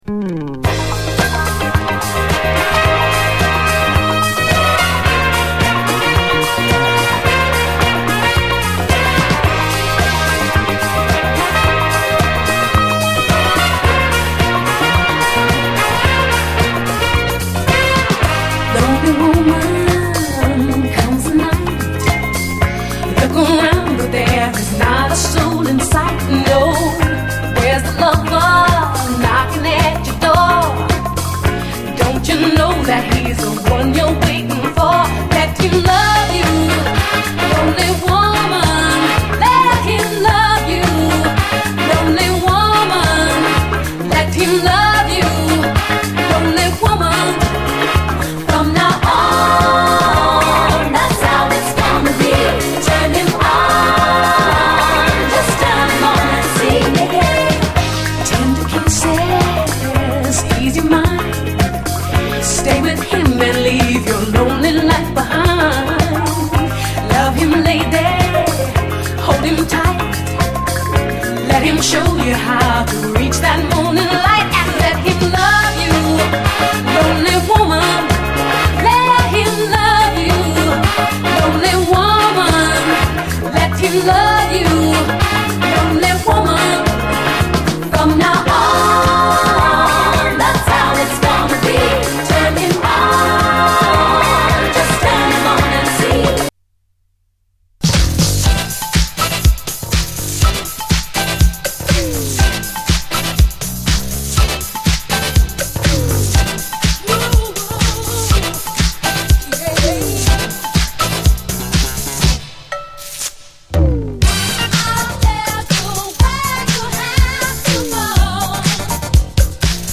SOUL, 70's～ SOUL, DISCO
最高トロピカル・ディスコ・ダブル・サイダー！